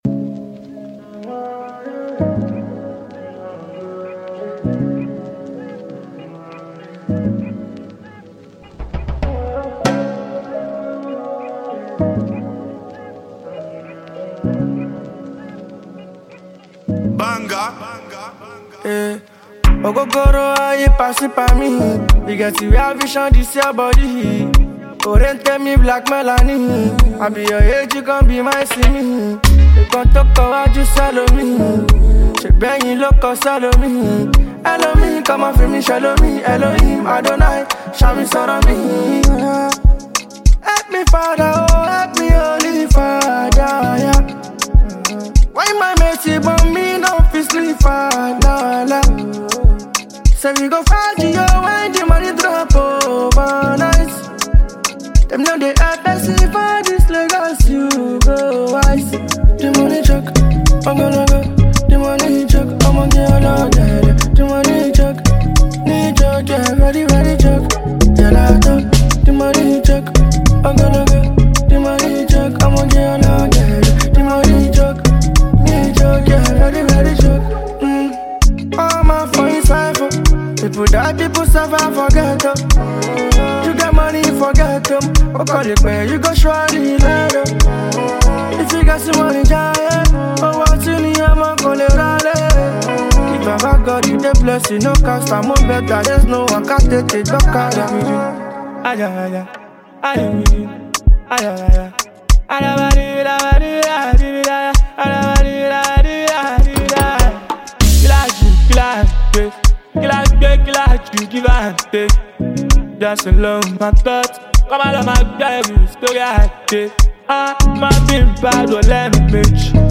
Latest Nigerian Song
Afrobeats
With nice vocals and high instrumental equipments